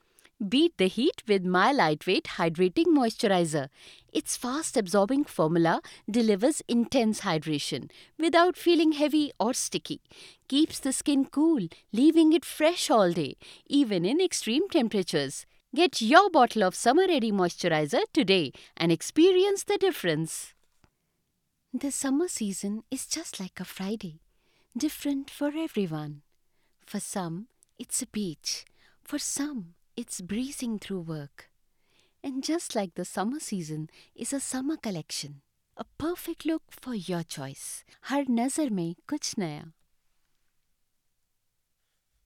Female Voice Over Talent, Artists & Actors
English (Indian)
Child (0-12) | Adult (30-50)